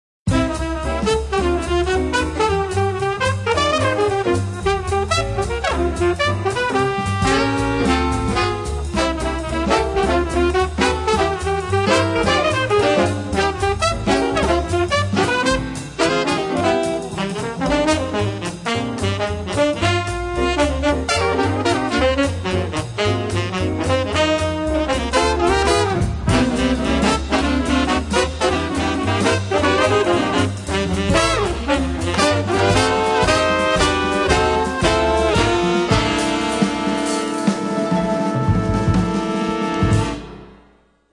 The Best In British Jazz
Recorded Curtis Schwartz Studios January 2003